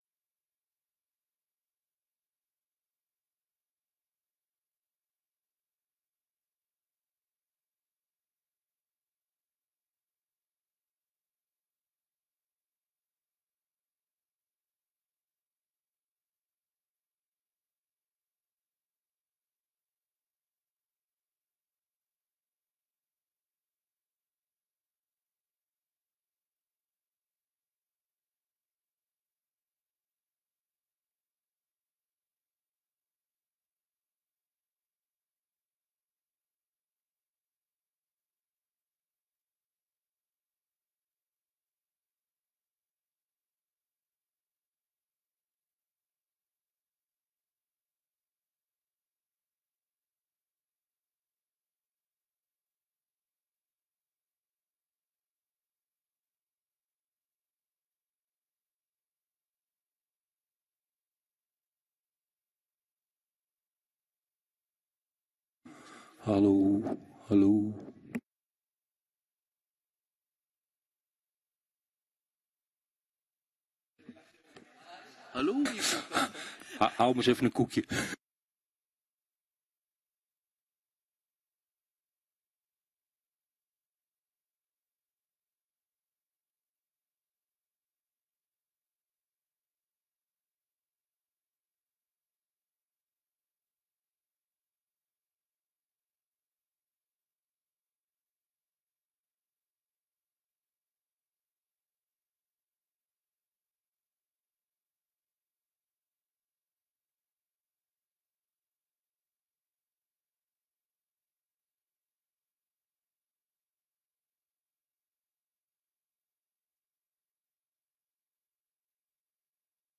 informatieve raadsbijeenkomst 12 april 2023 19:30:00, Gemeente Doetinchem
Locatie: Raadzaal